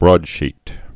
(brôdshēt)